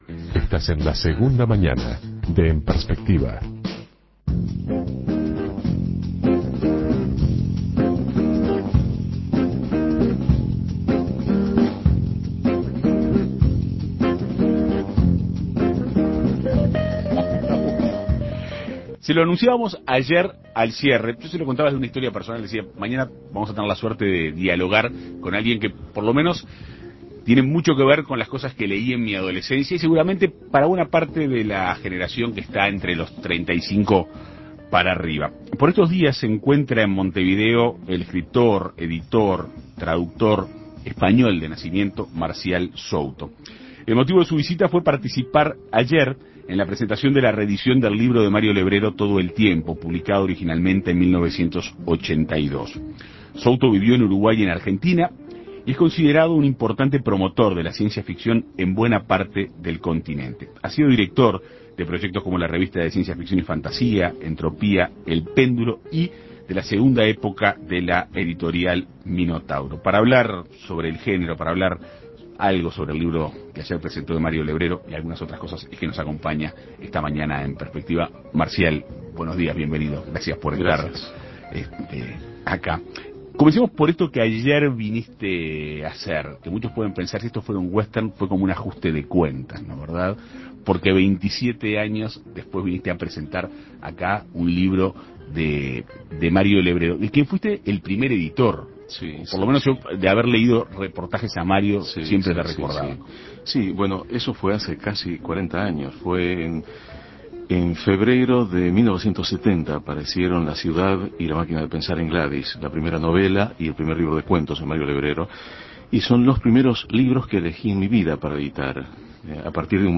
En Perspectiva Segunda Mañana lo entrevistó para hablar del género de ciencia ficción y del libro presentado.